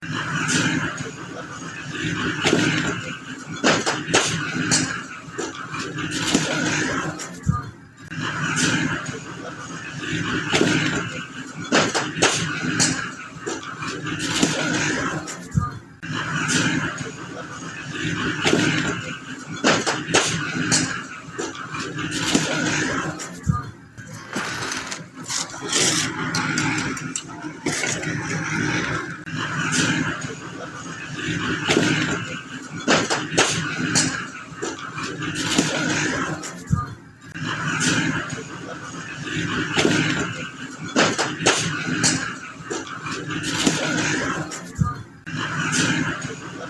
Tiếng phụ hồ Xúc Hồ, Cấp Vữa cho thợ xây…
Thể loại: Tiếng đồ vật
Âm thanh xẻng chạm đất, vữa trộn đều, tiếng kim loại va chạm tạo nên bản nhạc sống động, đặc trưng của nghề xây dựng.
tieng-phu-ho-xuc-ho-cap-vua-cho-tho-xay-www_tiengdong_com.mp3